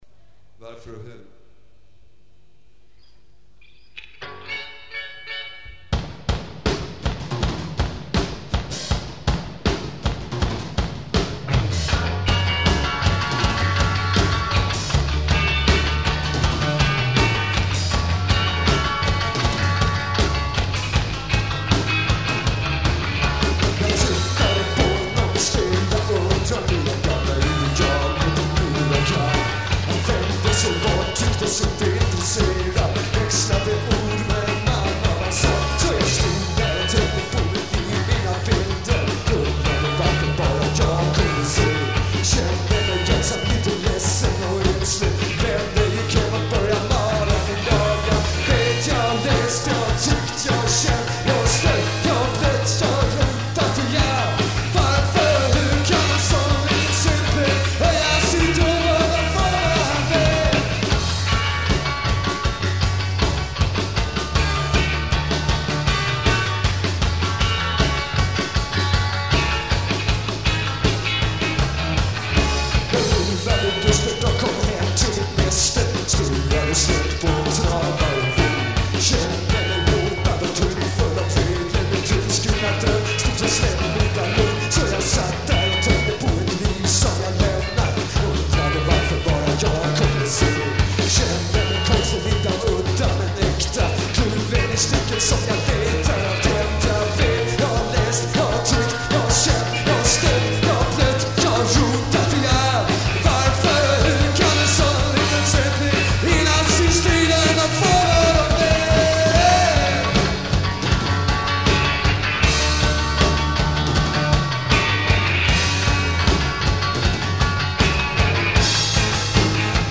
Bass
Guitar
Drums
At Arbis, November 9, 1983